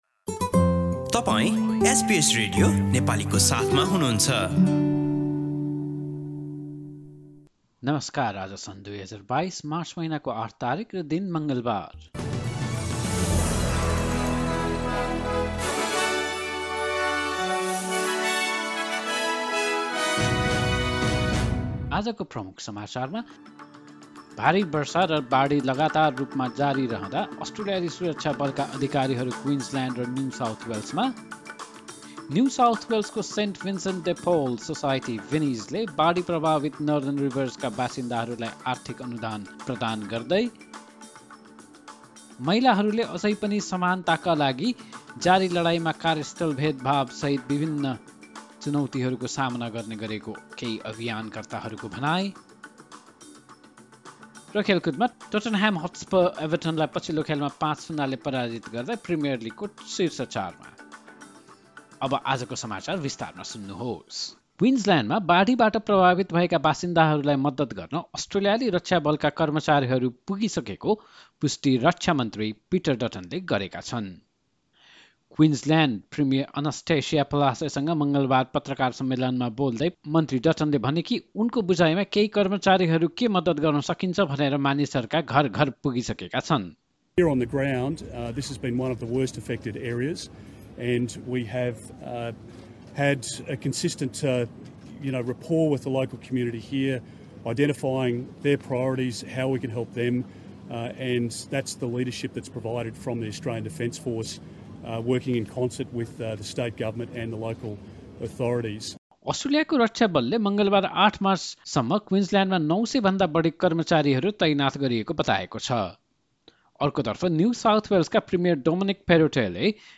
Listen to the latest news headlines from Australia in Nepali. In this bulletin, Australian defence force personnel arrive in Queensland and New South Wales amid continued heavy flooding and rainfall; St Vincent de Paul Society (Vinnies) of New South Wales offers financial grants to flood-affected Northern Rivers residents, and advocates say that women still face a range of challenges in their ongoing fight for equality, including workplace discrimination.